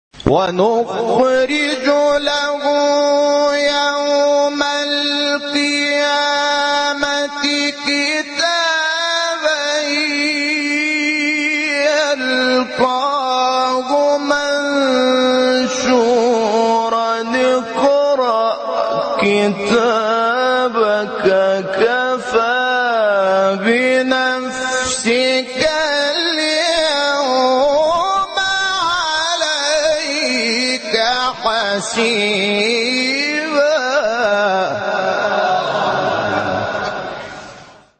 برچسب ها: خبرگزاری قرآن ، ایکنا ، فعالیتهای قرآنی ، مقاطع صوتی ، فراز صوتی ، تلاوت ، راغب مصطفی غلوش ، شحات محمد انور ، محمد الفیومی ، محمود شحات انور ، محمد عبدالعزیز حصان ، متولی عبدالعال ، مقطع میلیونی مصطفی اسماعیل ، قرآن